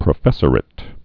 (prə-fĕsər-ĭt)